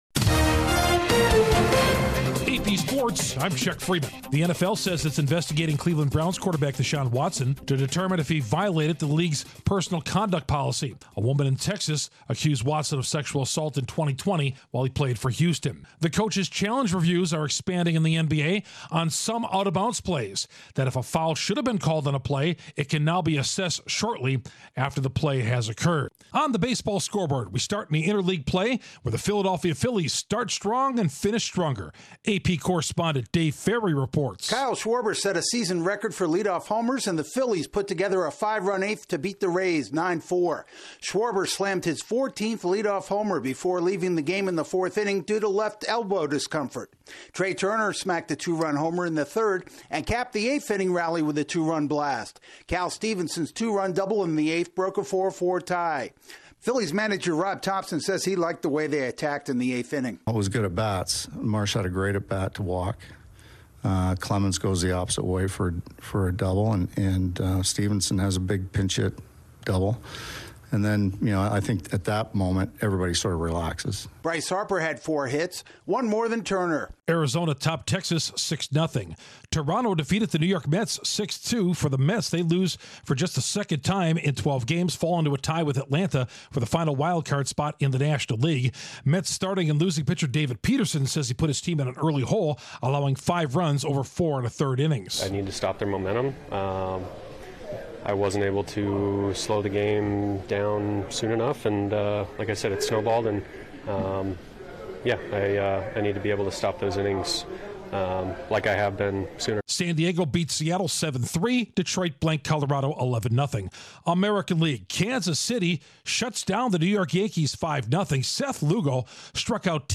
The NFL is looking into civil lawsuit against Browns' Watson, the NBA expands coaches challenges, Kyle Schwarber sets an MLB record and gamblers come at an Auburn quarterback. Correspondent